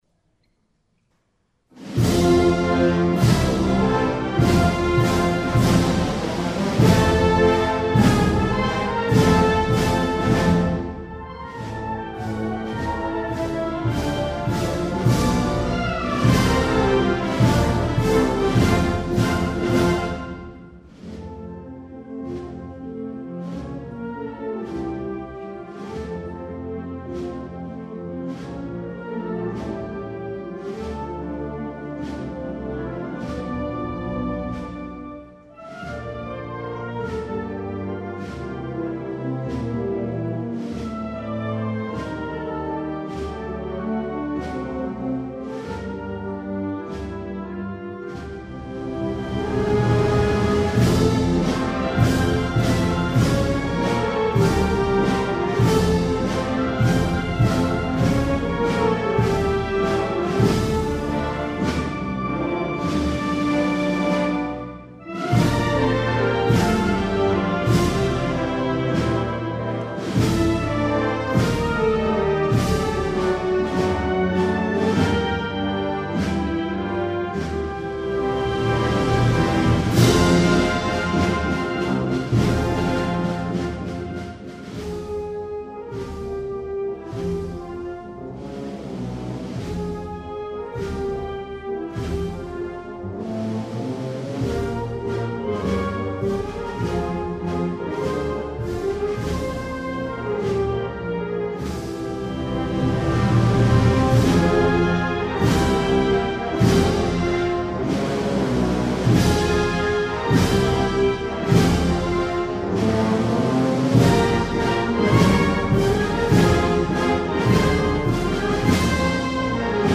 Marchas procesionales
Para Bandas de Música